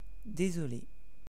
English French IPA pronunciation (Canadian accent) IPA pronunciation (French accent)
I'm sorry Pardon / Je suis désolé (if male) / Je suis désolée (if female) /paʀdɔ̃/ / /dezɔle/ /paʁdɔ̃/ /
/dezɔle/
Fr-Désolé.oga.mp3